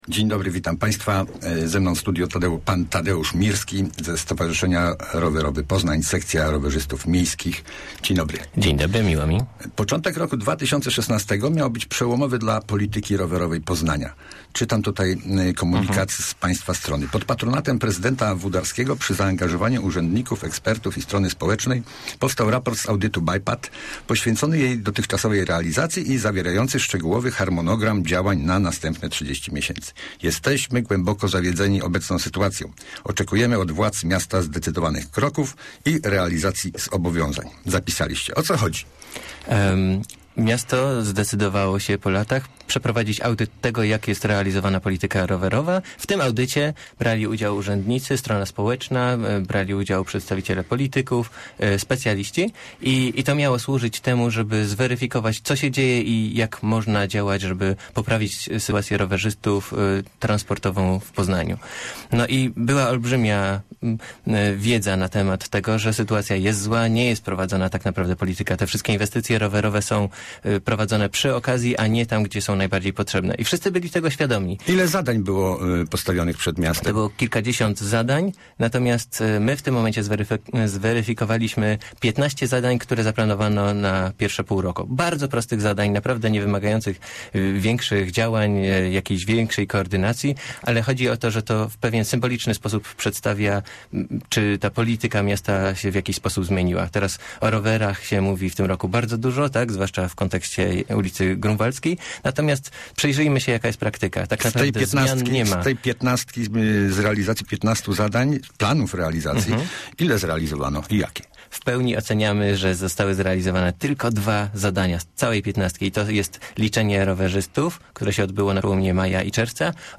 95jrf8ngbf96bwp_rozmowa_o_rowerowej_kaponierze.mp3